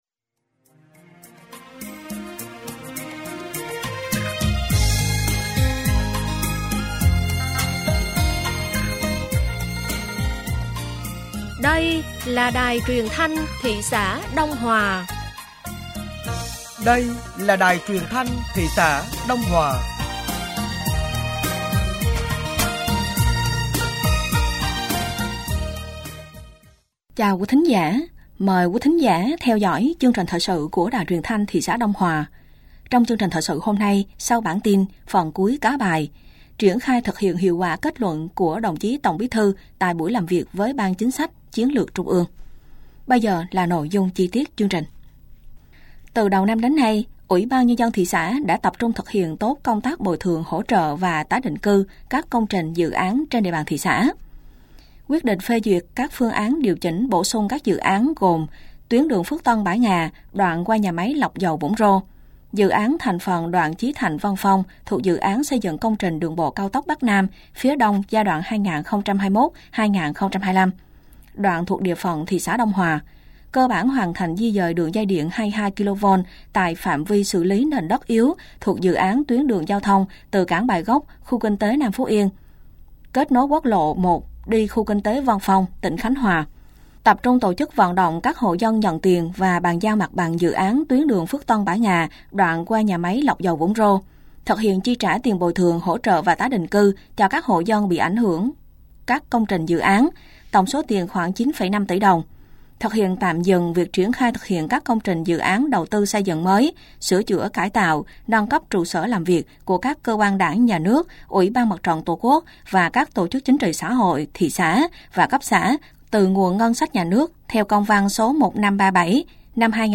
Thời sự tối ngày 10 và sáng ngày 11 tháng 4 năm 2025